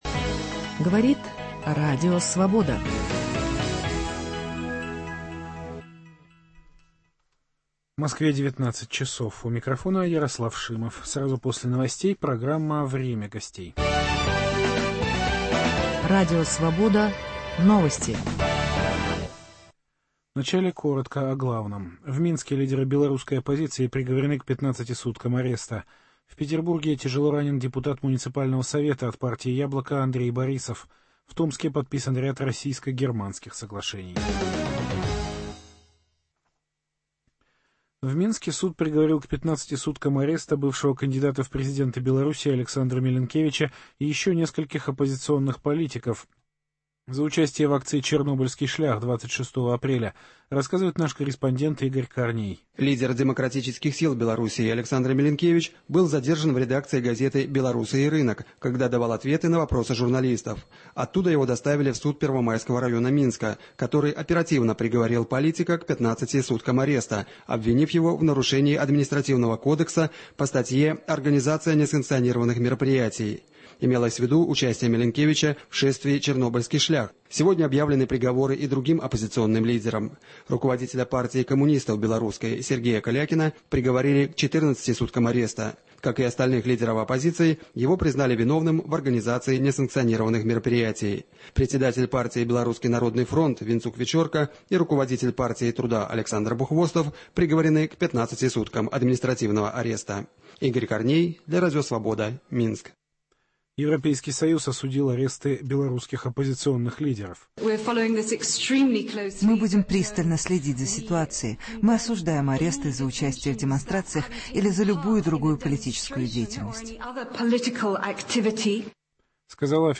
В студию программы «Время гостей» приглашен заместитель председателя российской демократической партии «Яблоко» Сергей Иваненко.